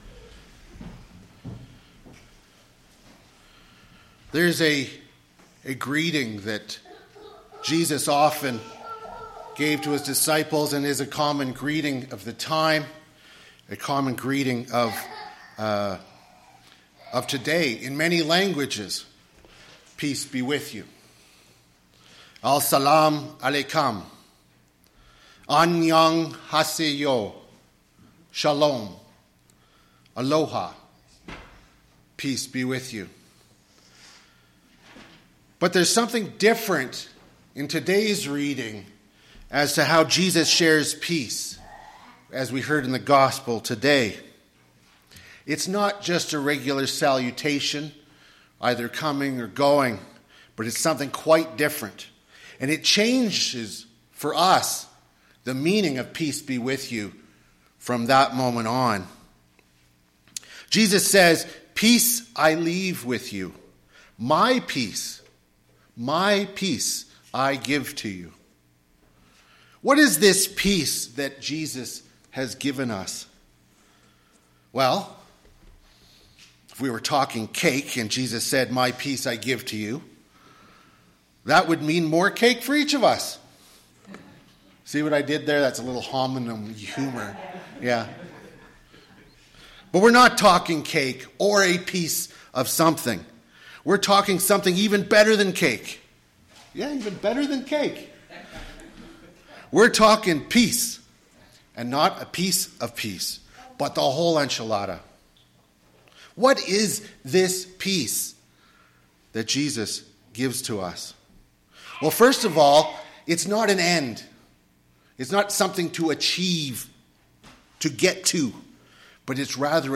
Sermons | St. Michael Anglican Church